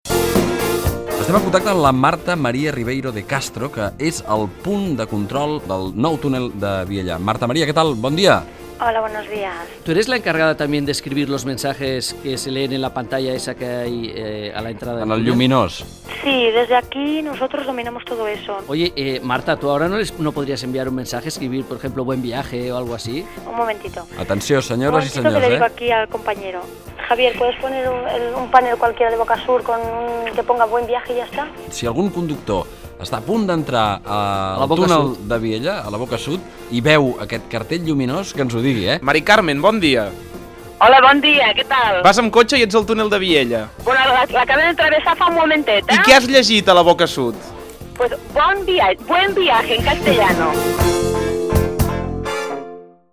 Promoció del programa
Entreteniment